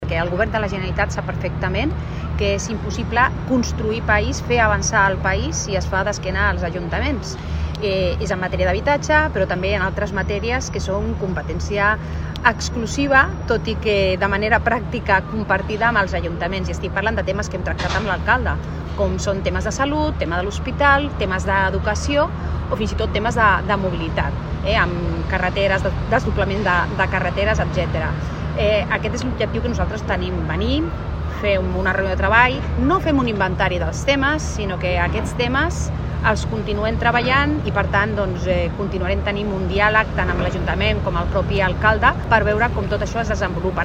Pilar Díaz, delegada del Govern Generalitat a Barcelona